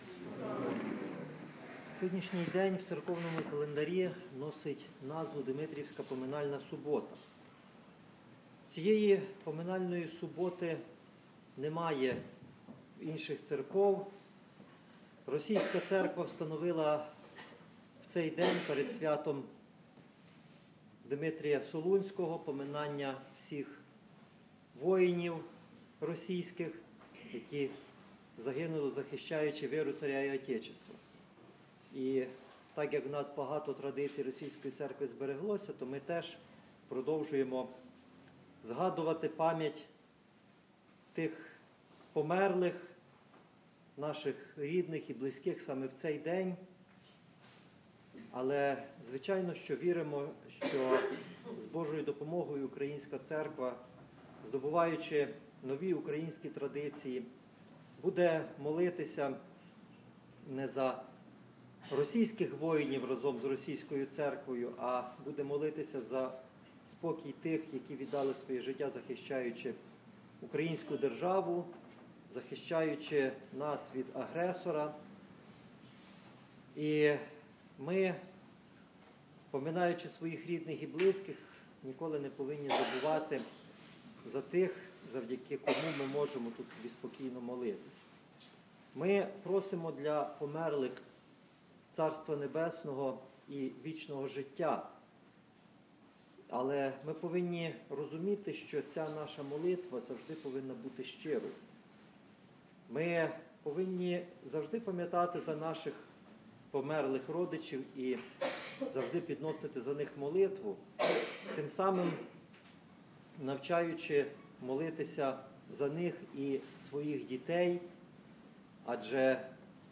2 листопада 2019 року, у Димитрівську поминальну суботу, Високопреосвященнійший Нестор, архієпископ Тернопільський і Кременецький, звершив Божественну літургію в каплиці кафедрального собору свв.Костянтина та Єлени м. Тернополя.